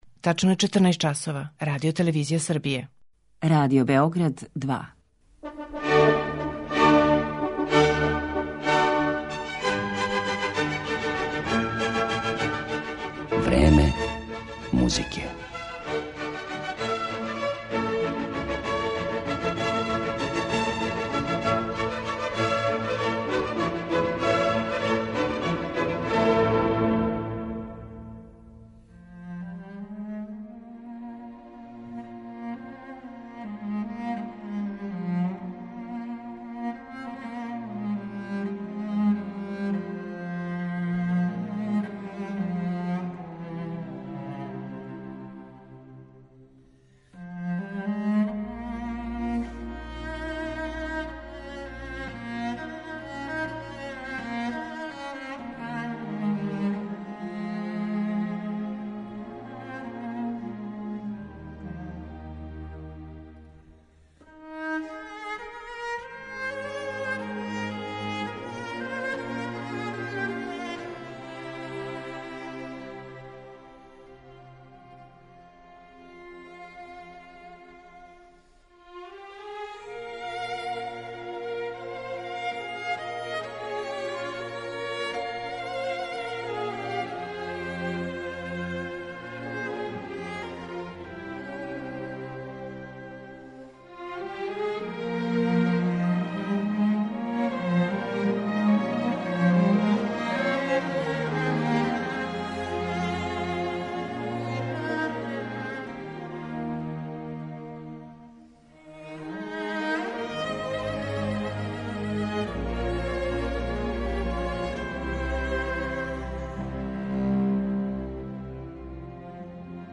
британска виолончелисткиња
топао тон, богат колорит и експресиван израз